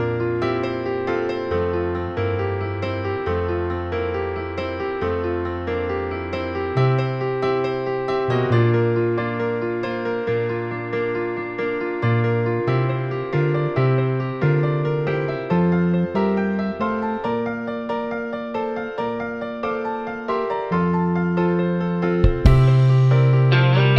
no Backing Vocals Rock 4:30 Buy £1.50